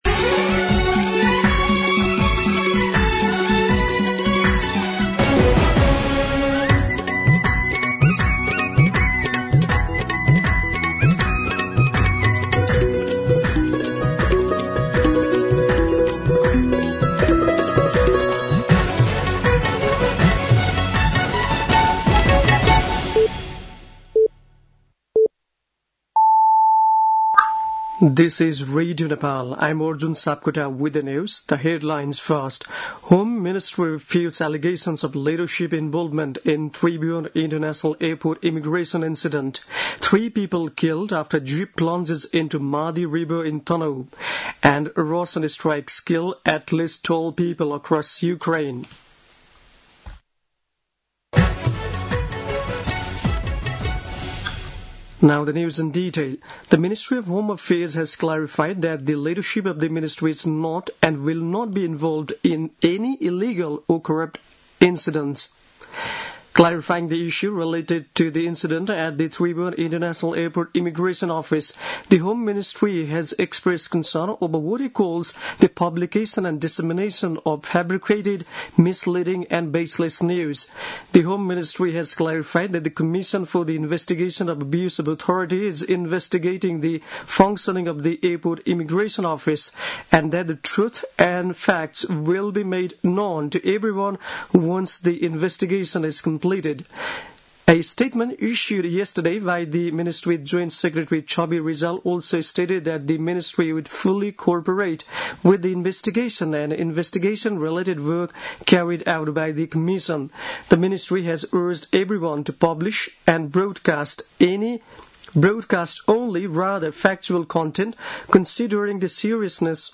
दिउँसो २ बजेको अङ्ग्रेजी समाचार : ११ जेठ , २०८२